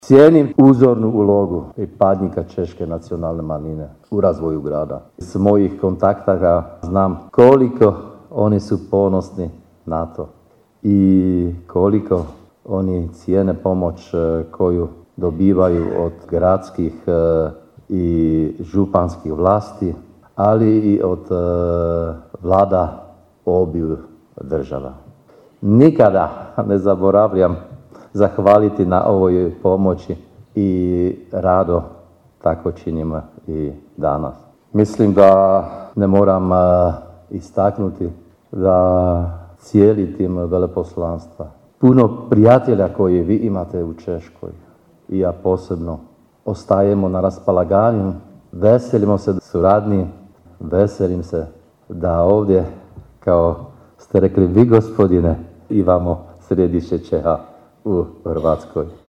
Svečana sjednica u povodu Dana Grada Daruvara održana je u dvorani Gradskog kina Pučkog otvorenog učilišta.